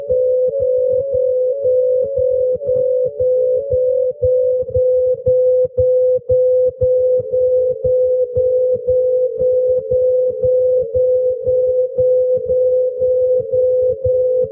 It indicates a signal not transmitted in Morse code, such as random sequences of dots and dashes, continuous transmission of dashes or dots, etc.
Transmission of groups of 16 dashes, or continuous dashes.